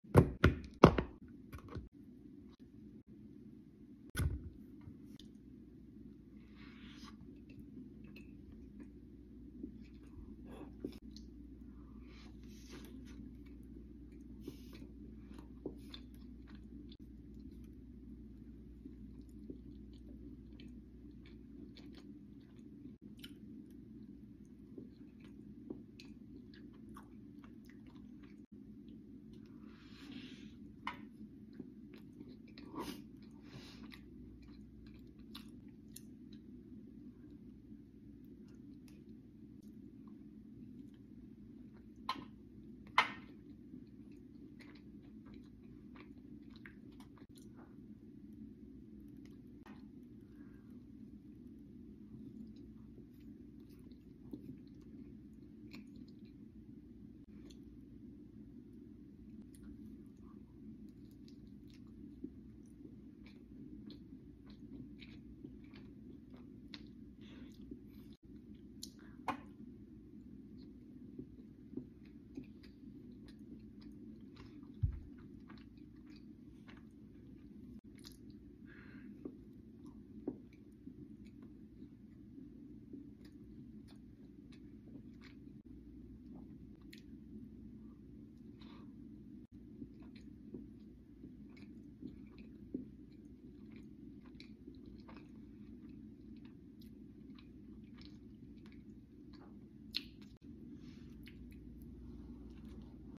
Cookie ice cream sandwich mukbang! sound effects free download